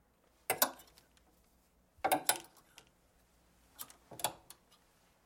Bathroom » opening shower curtain
描述：opening shower curtain
声道立体声